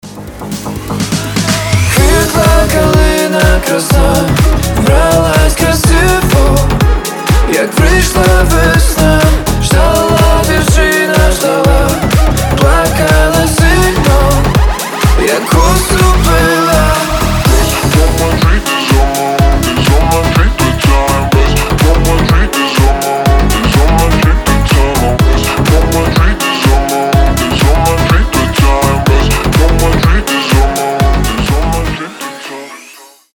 • Качество: 320, Stereo
мужской голос
Club House
ремиксы